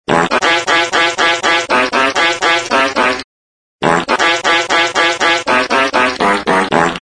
Play Fart Meme - SoundBoardGuy
Play, download and share Fart Meme original sound button!!!!
fart-meme.mp3